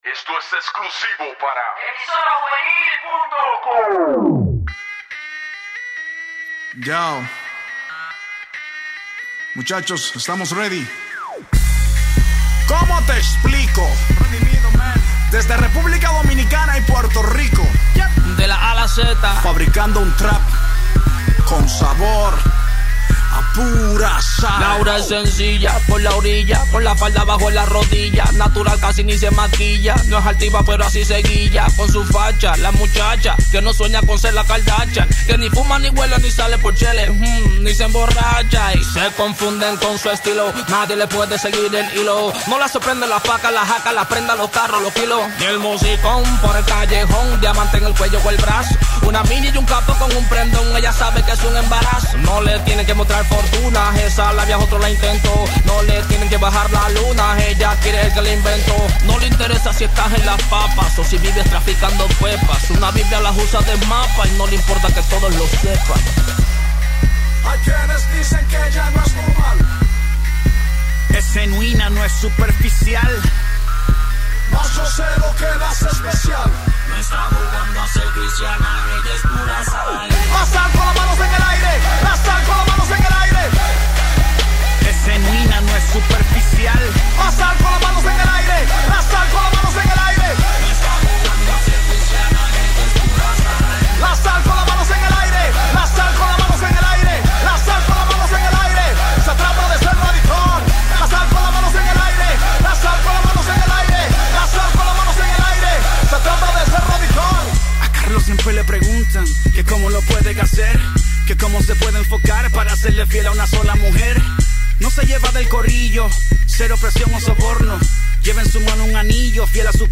música urbana cristiana
cantando el estilo trap como si fueran nativos en el mismo